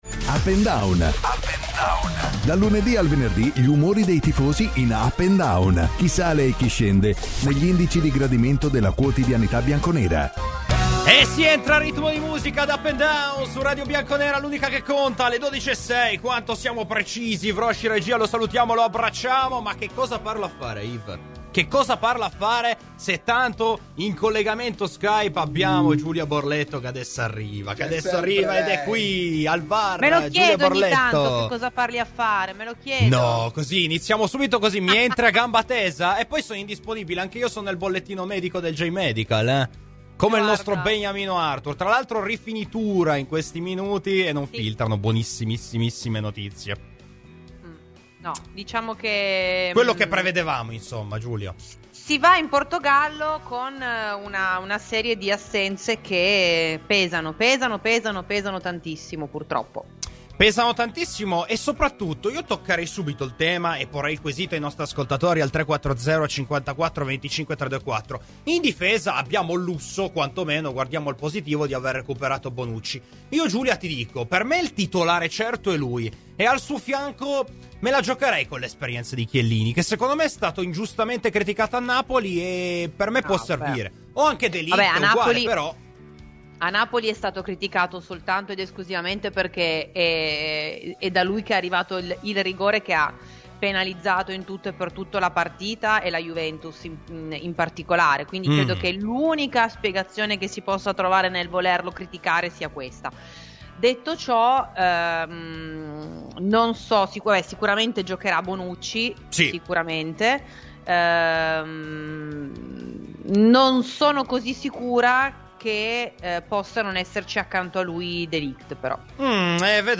Ospiti : Daniele Fortunato (ex calciatore Juventus).